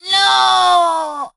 Penny Portrait.png潘妮是海盗船上的一员，声音像个假小子，其实超级可爱(●'◡'●)
Penny_die_vo_01.ogg